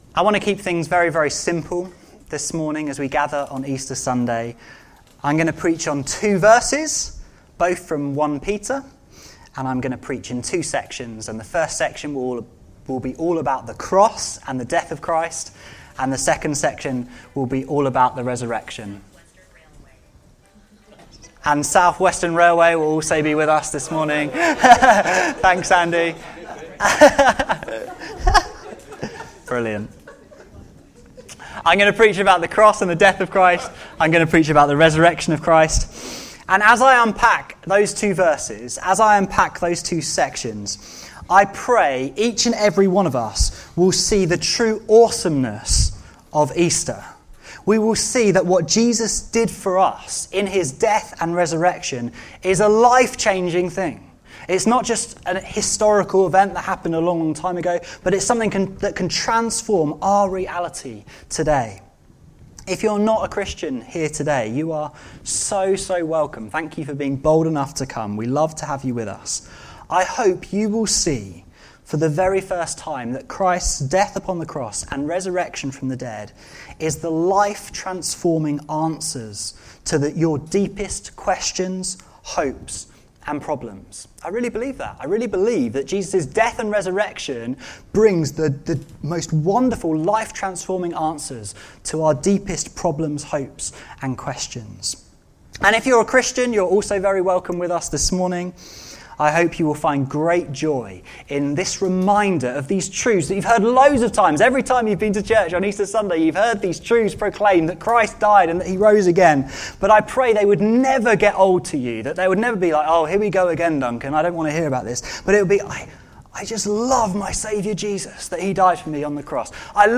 Easter Sermons